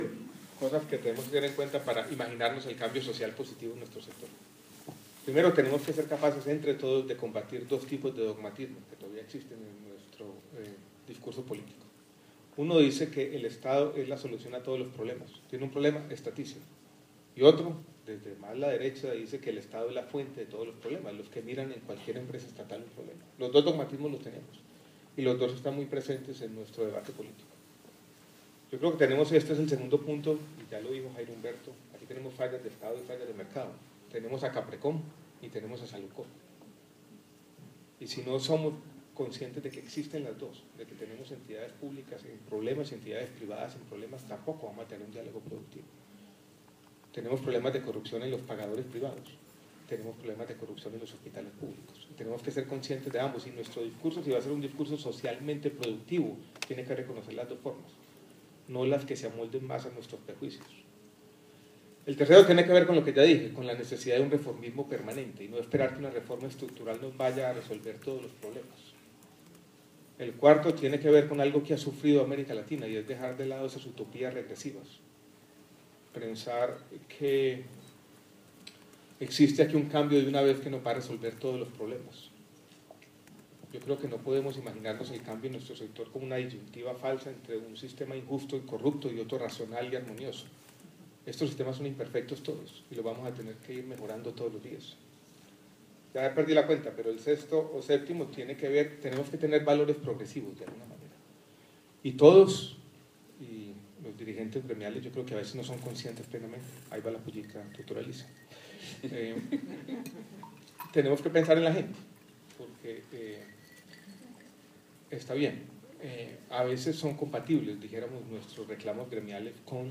-Esta y otras inquietudes fueron resueltas por el Ministro Alejandro Gaviria durante el XII Congreso Nacional de la Asociación Colombiana de Empresas Sociales del Estado y Hospitales Públicos (ACESI).
-Audio: declaraciones de Alejandro Gaviria Uribe